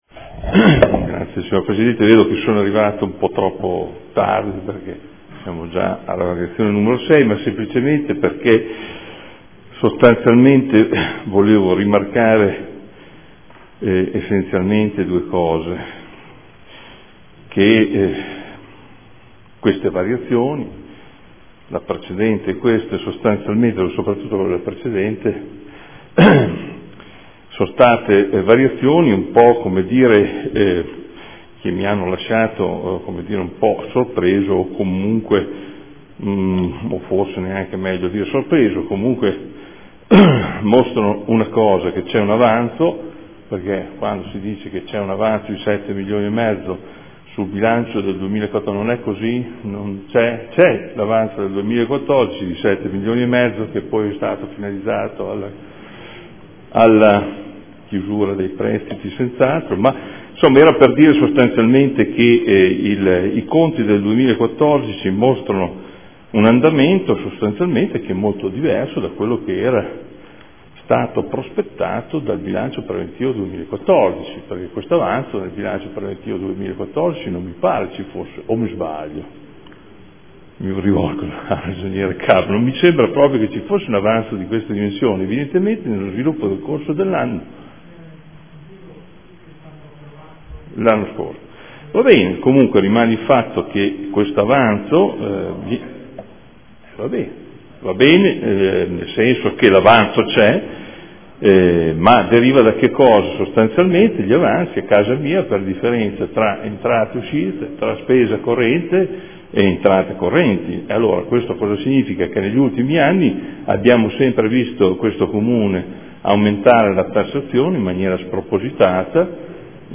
Adolfo Morandi — Sito Audio Consiglio Comunale
Seduta del 27/11/2014 Dichiarazione di voto. Delibera. Bilancio di previsione 2014 / 2016 – Programma Triennale dei Lavori Pubblici 2014 / 2016 – Variazione di bilancio n. 6